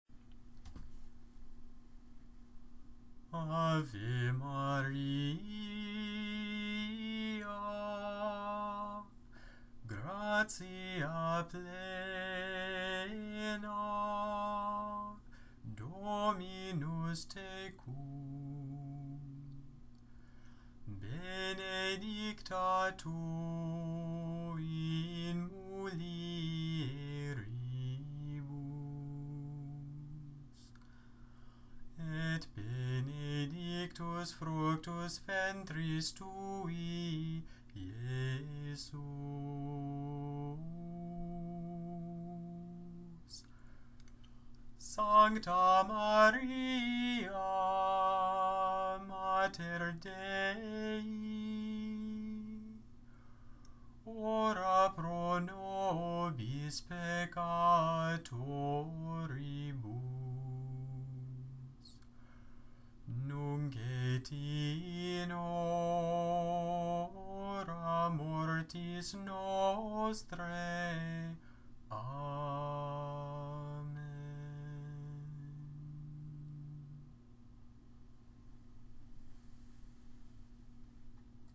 Gregorian, Catholic Chant Ave Maria